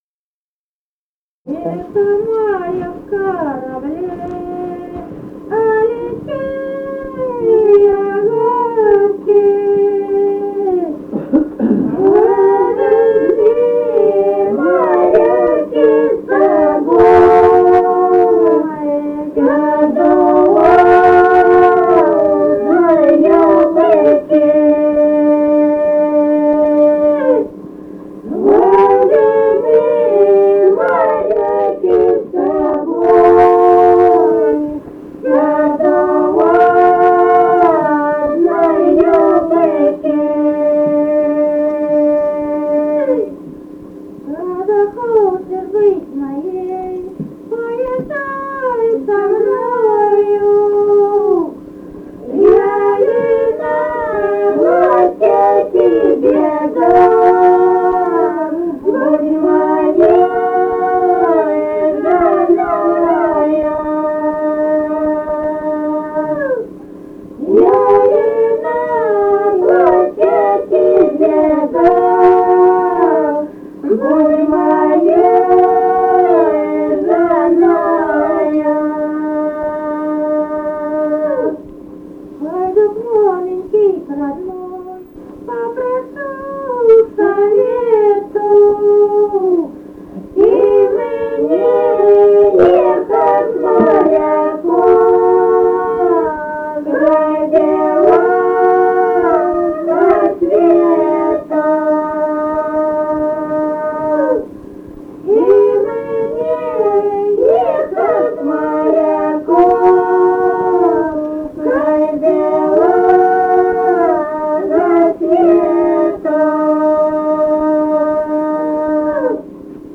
Музыкальный фольклор Климовского района 061. «Ехал моряк в корабле» (лирическая).